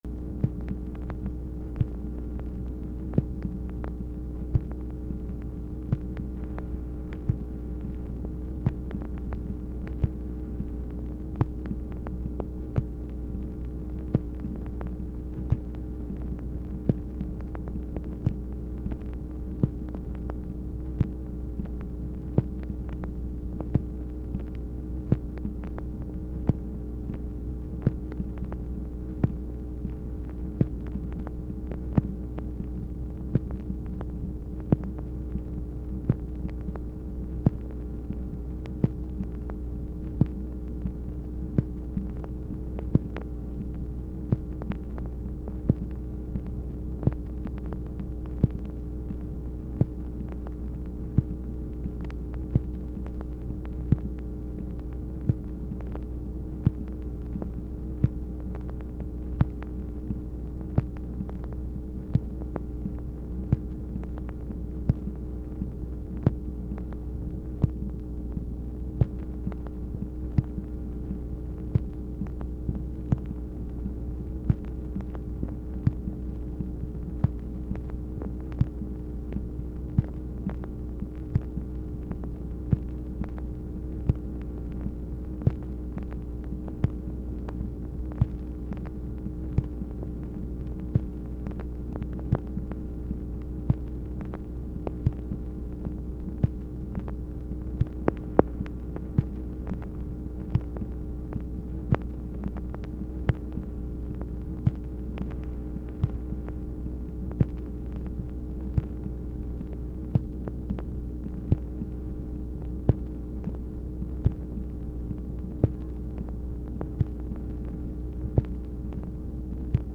MACHINE NOISE, January 22, 1964
Secret White House Tapes